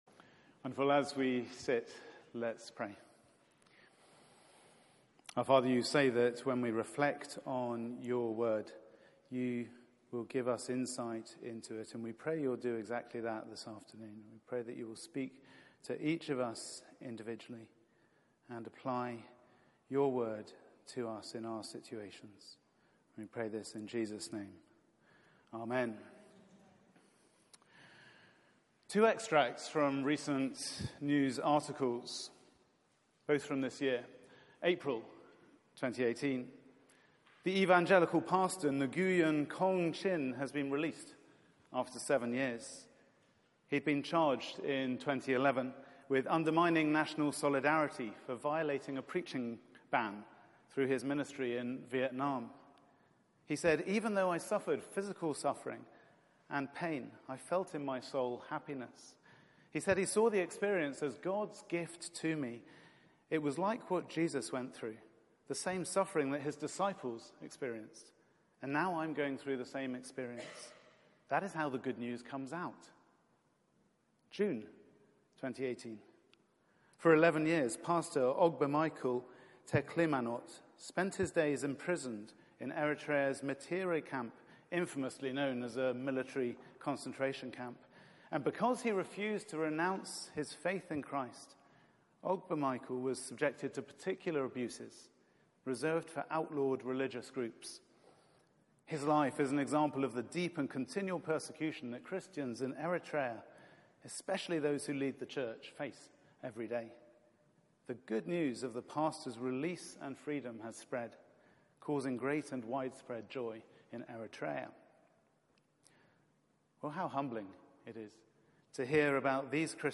Media for 4pm Service on Sun 16th Sep 2018 16:00 Speaker
2 Timothy 2:1-13 Series: Be confident in God... Theme: ...and not discouraged by hardship Sermon Search the media library There are recordings here going back several years.